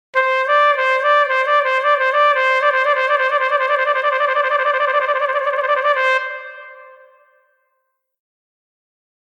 Les prochains extraits audio que vous entendrez dans ce billet seront tous agrémentés de cette réverbe.
C) Les trilles :
Trill.mp3